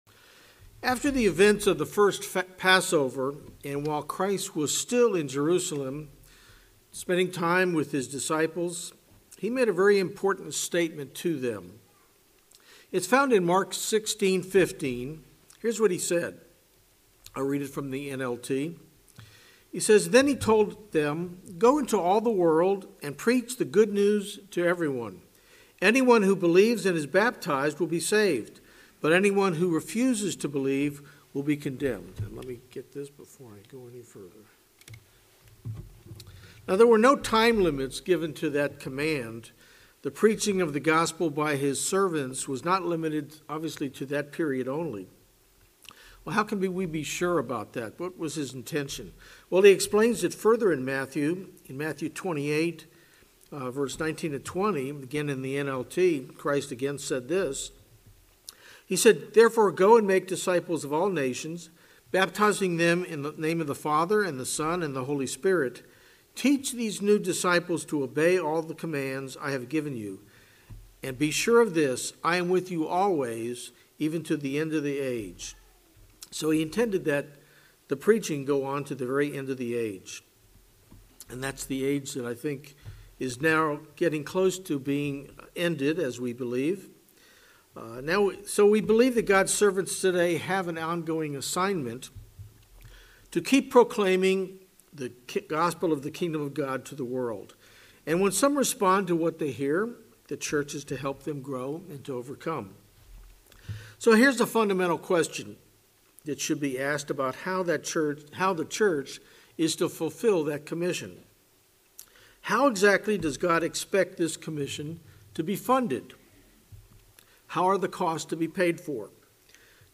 The program of tithing covers many of the vital funding needs of the church and we as individuals. This sermon covers some basic information about what God says about tithing.